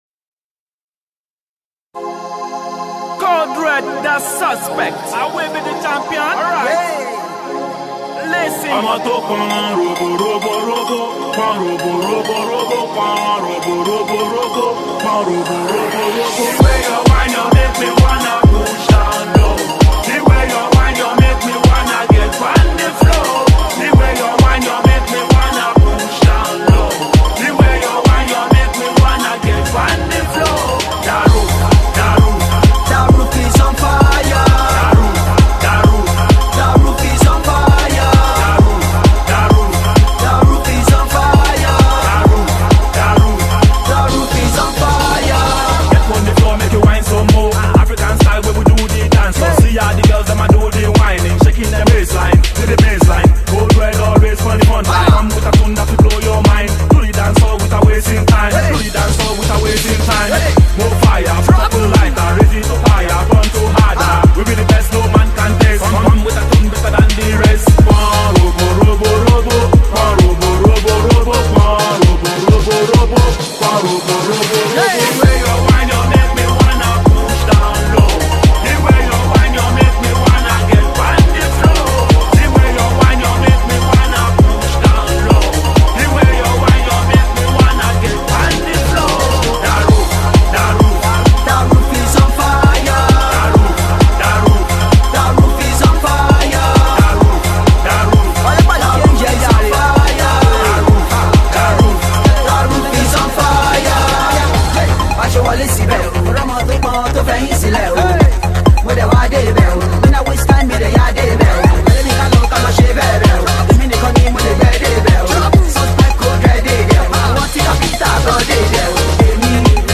mp3 0:00 EuroDance_ Скачать